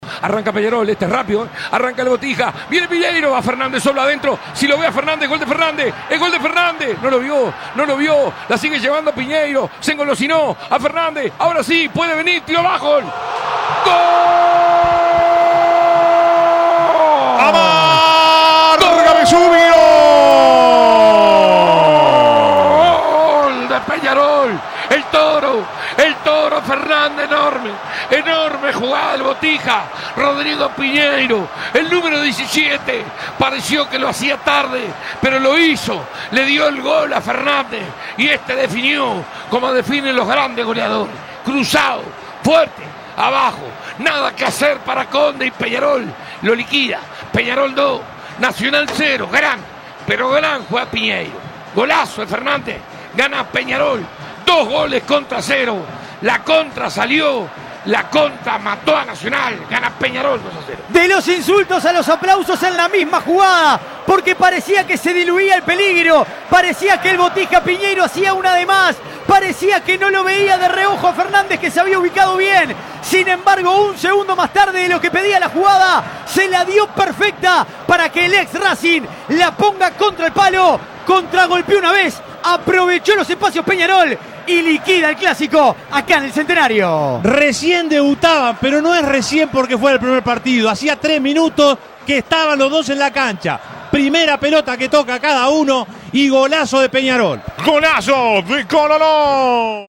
Reviví los goles con el relato de Alberto Sonsol por El Espectador.
Cancha: Estadio Centenario. Público: 35.000 personas.